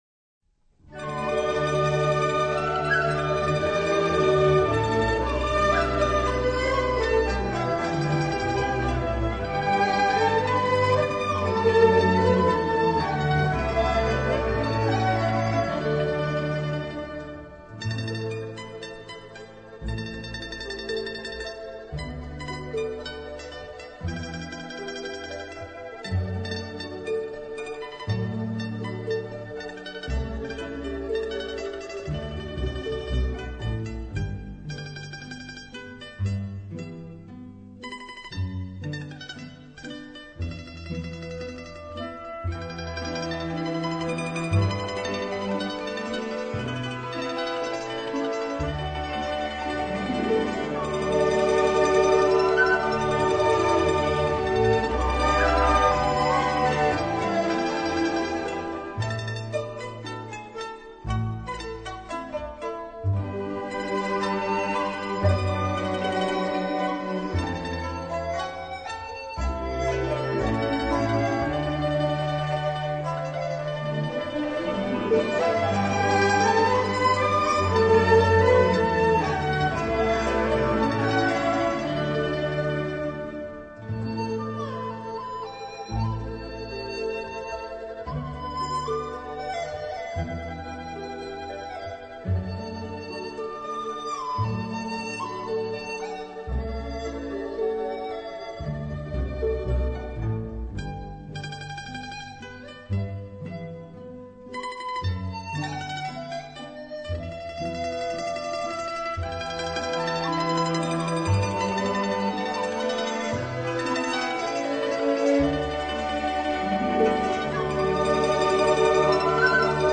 中国民乐之经典，70-80年代古朴的民乐配器，高质量的录音水平，尽显往日民乐之魅力和情怀
民乐合奏